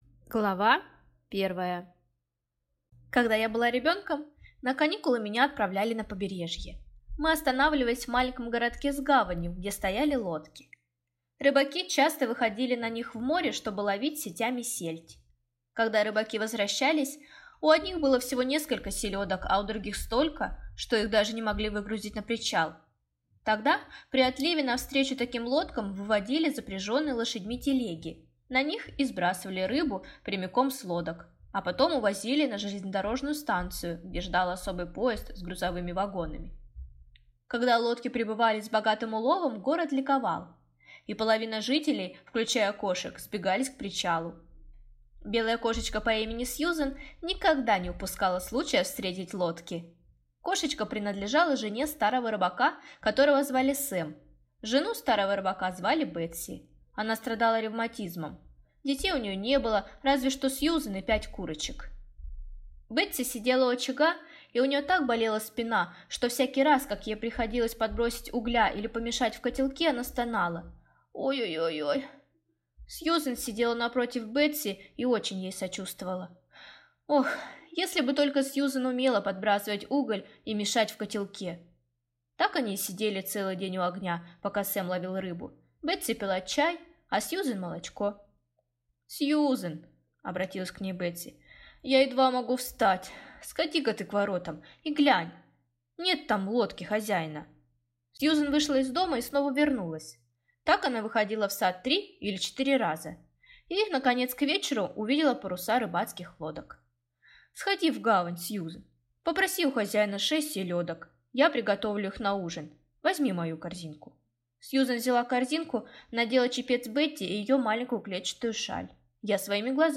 Аудиокнига Сказка о поросёнке Робинсоне | Библиотека аудиокниг